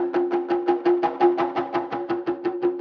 Index of /musicradar/rhythmic-inspiration-samples/85bpm
RI_DelayStack_85-04.wav